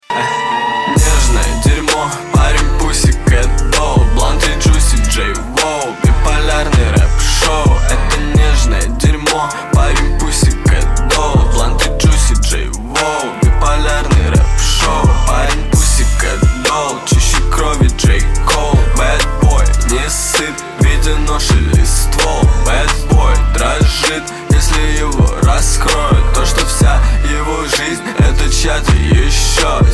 • Качество: 128, Stereo
русский рэп
качающие
Bass
нецензурная лексика